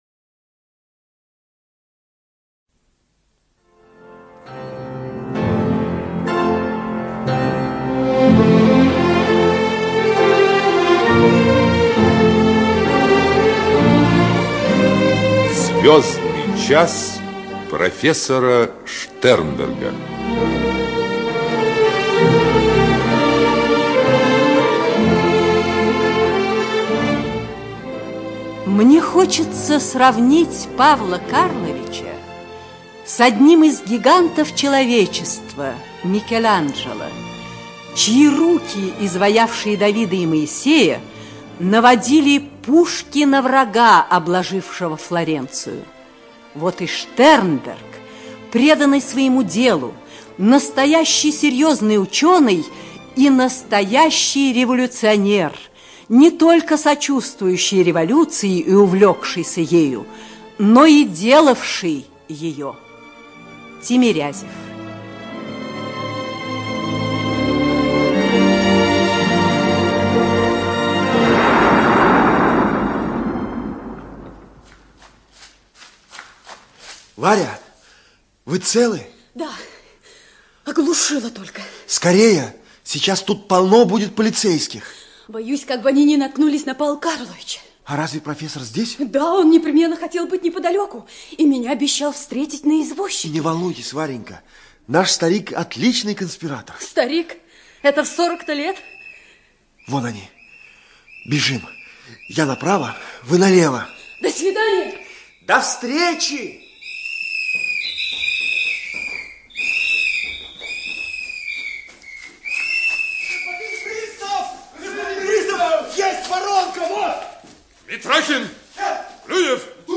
ЖанрРадиоспектакли